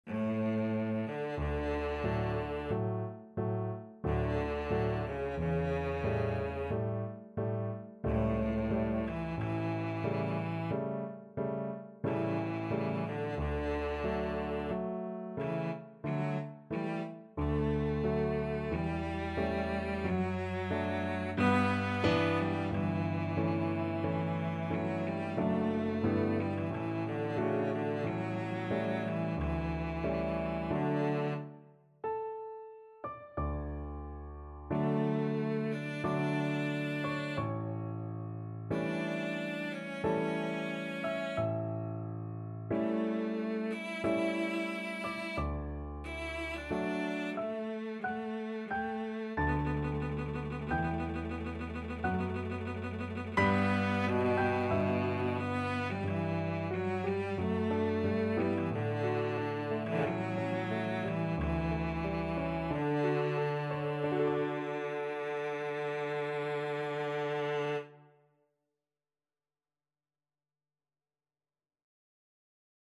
Cello
3/4 (View more 3/4 Music)
D major (Sounding Pitch) (View more D major Music for Cello )
Adagio =45
Classical (View more Classical Cello Music)